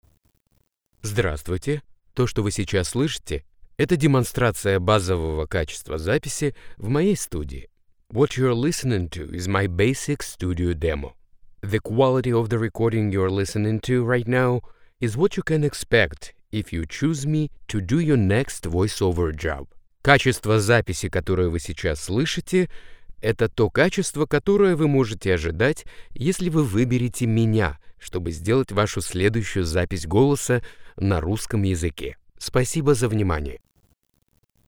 My studio is based at my apartment:
4×6 Double-wall vocal booth by “Whisper Room”
FiOS Internet Connection STUDIO SOUND QUALITY RAW
Declaration-of-Quality-RAW.mp3